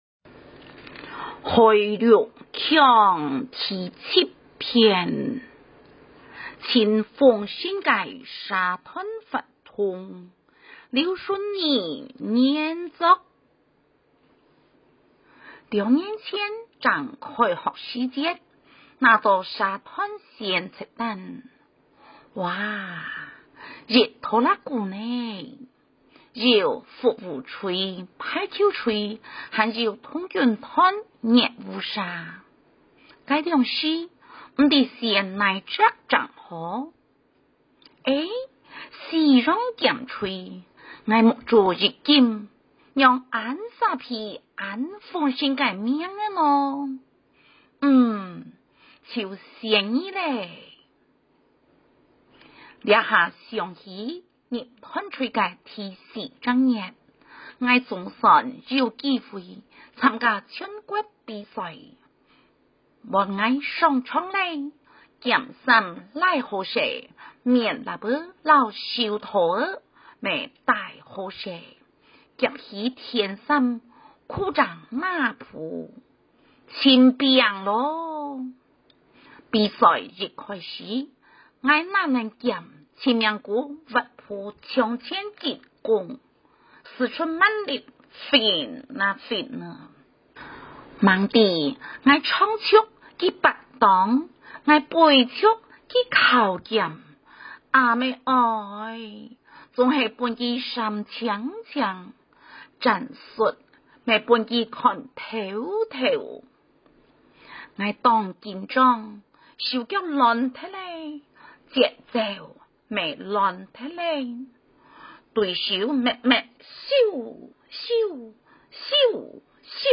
112學年度校內國語文競賽客家語朗讀稿及音檔，供參賽同學練習參考！